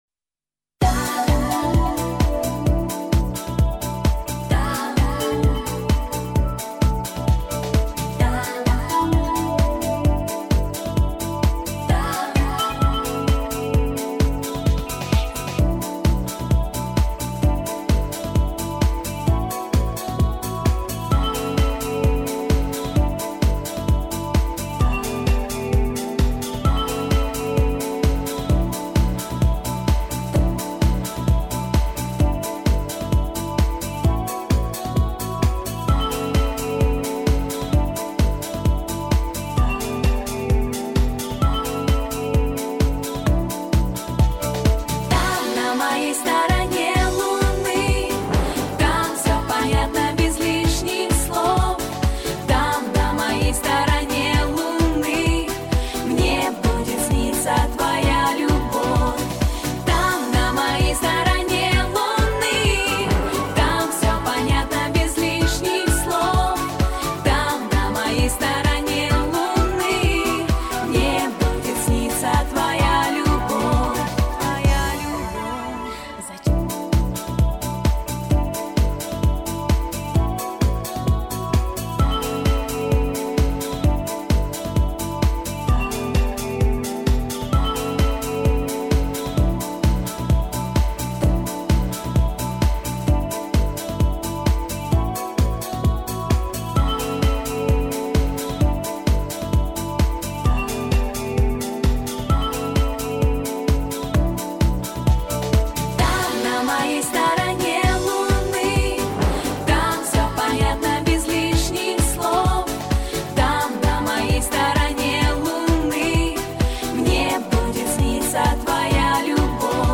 минусовка версия 45958